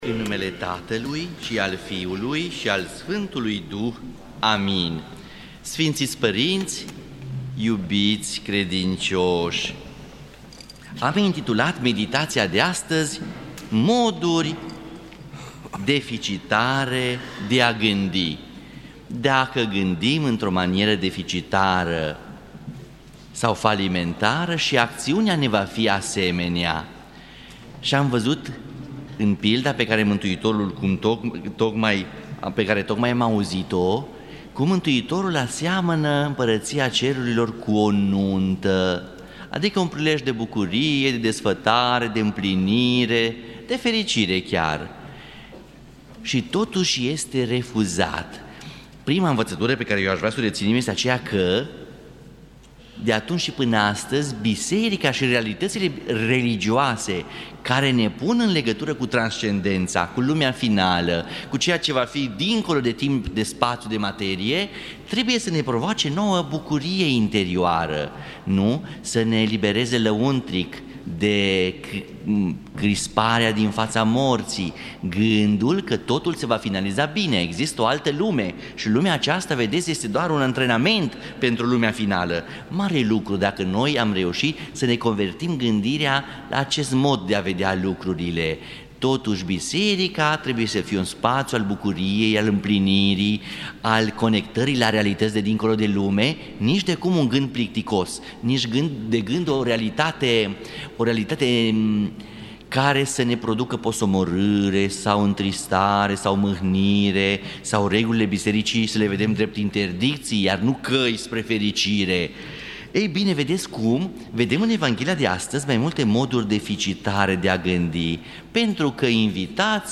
Moduri deficitare de a gândi | Predică la Duminica a 14-a după Rusalii Play Episode Pause Episode Mute/Unmute Episode Rewind 10 Seconds 1x Fast Forward 30 seconds 00:00